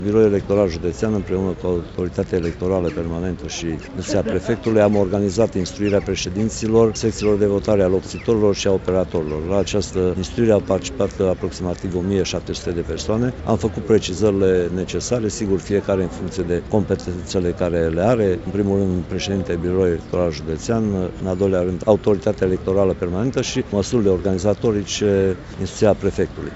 Prefectul județului Mureș, Mircea Dușa.